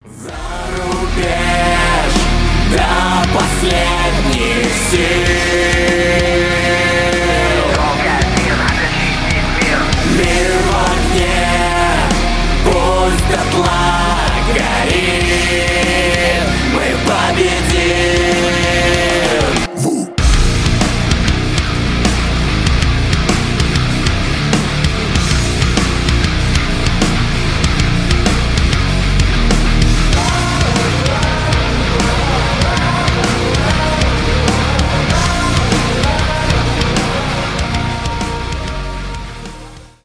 рок , метал , nu metal